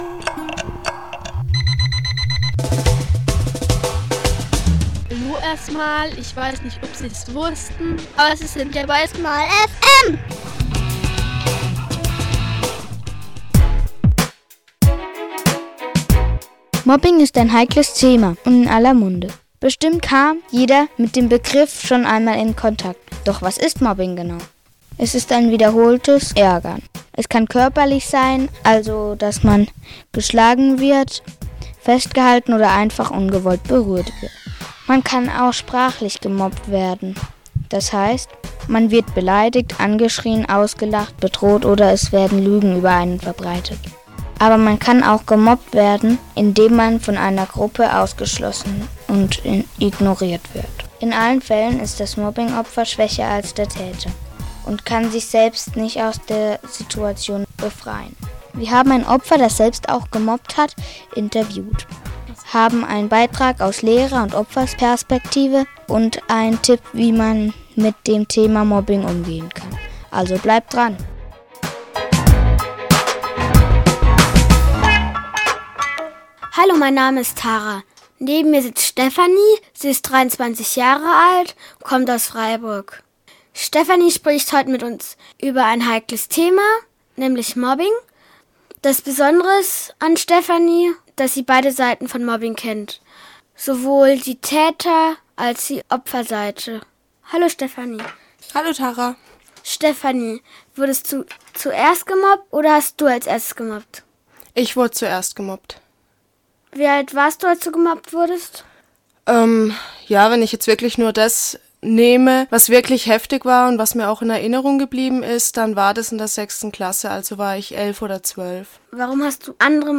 Dieser Eintrag wurde veröffentlicht unter Gebauter Beitrag Gesellschaft & Soziales Kinder PH 88,4 Schule & Co. und verschlagwortet mit Psychologie SmallFM deutsch am von